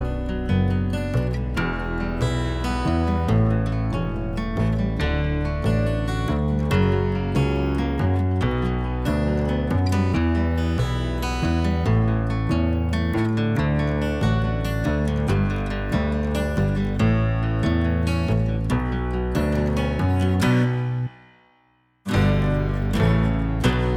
Minus Lead Pop (1970s) 4:58 Buy £1.50